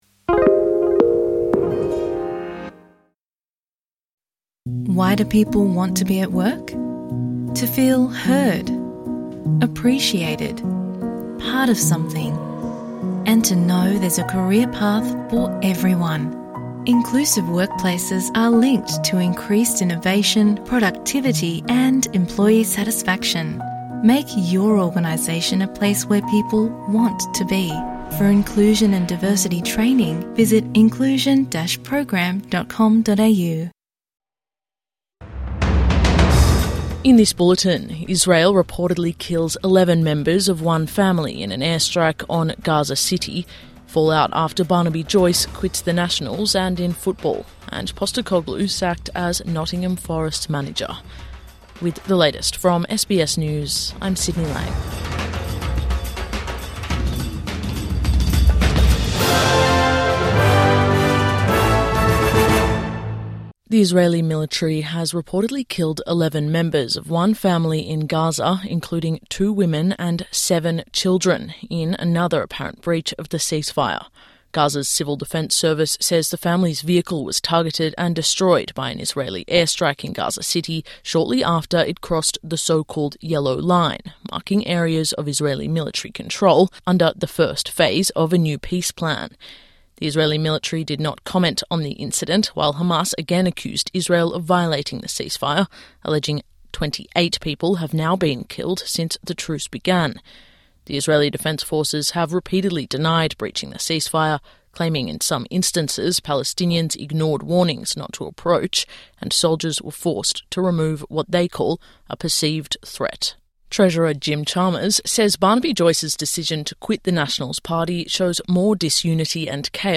Morning News Bulletin 19 October 2025